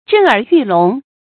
注音：ㄓㄣˋ ㄦˇ ㄧㄩˋ ㄌㄨㄙˊ
震耳欲聾的讀法